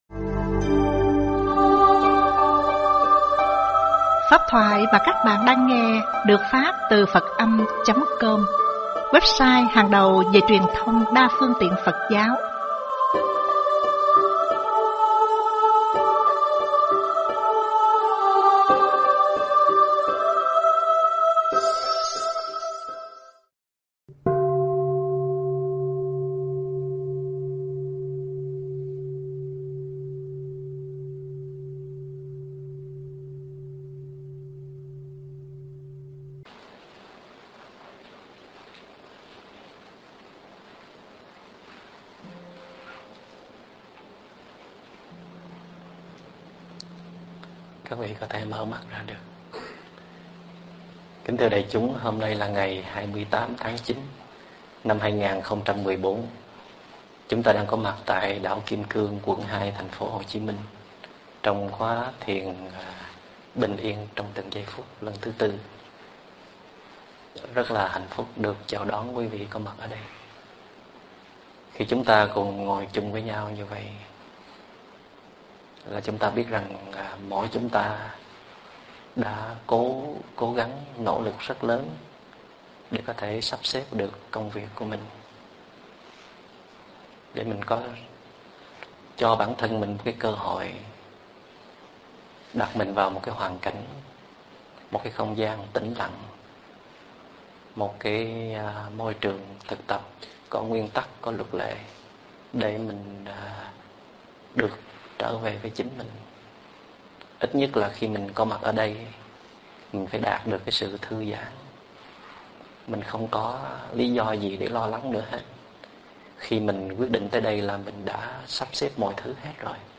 Mp3 Thuyết Pháp